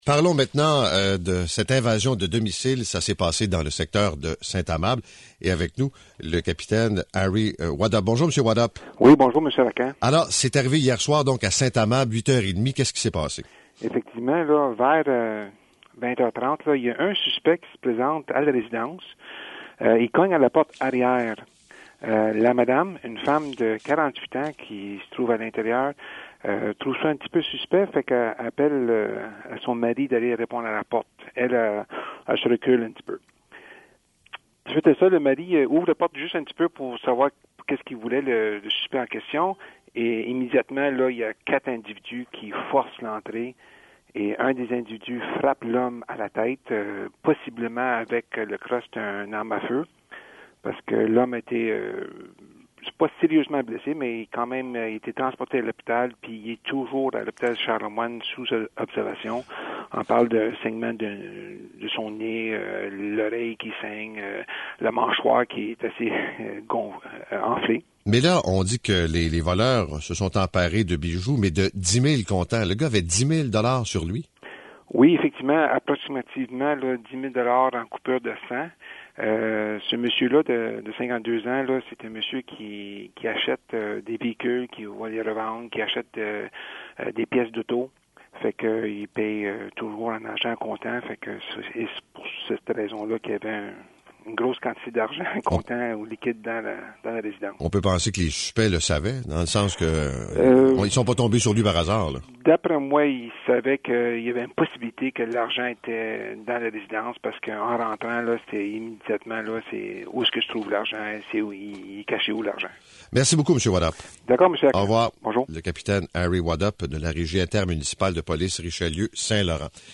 Source : diffusé 19/2/2013, Paul Arcand, Puisqu’il faut se lever, 98,5fm, Montréal, Québec.